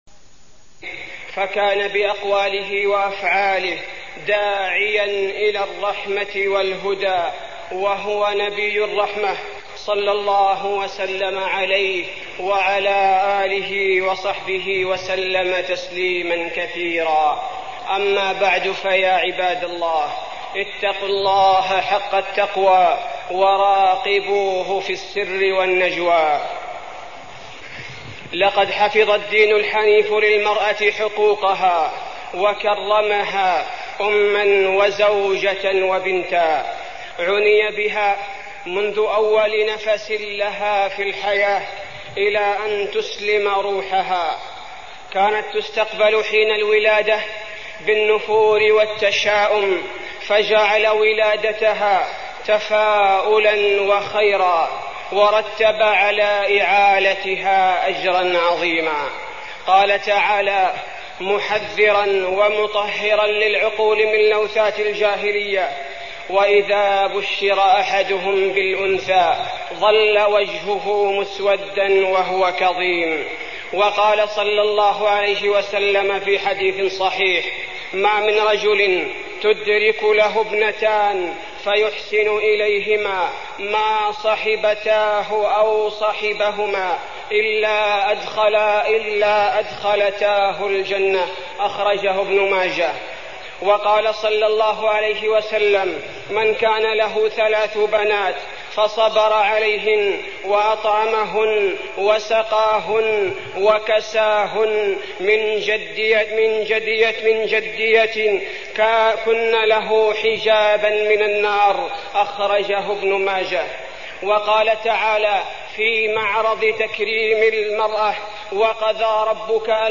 تاريخ النشر ١٣ ربيع الثاني ١٤١٣ المكان: المسجد النبوي الشيخ: فضيلة الشيخ عبدالباري الثبيتي فضيلة الشيخ عبدالباري الثبيتي حقوق المرأة